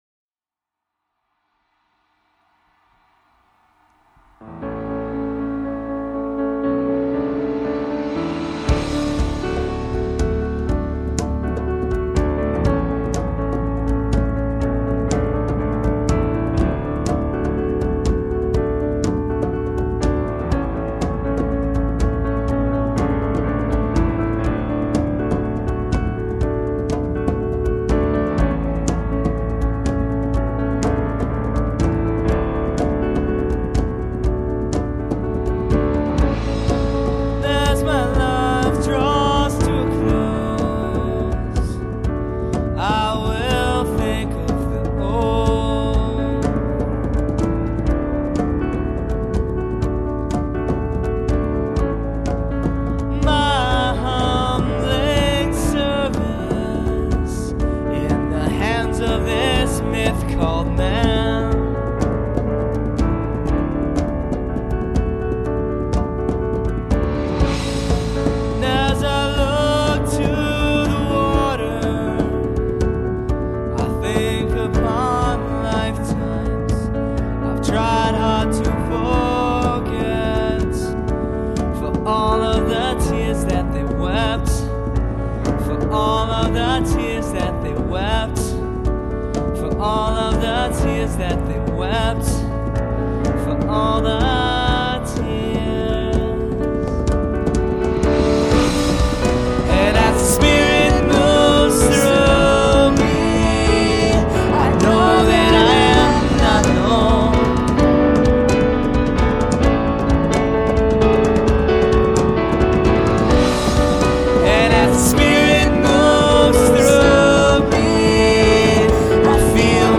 Songs 1-8 recorded in Rochester, MN, Fall 2001.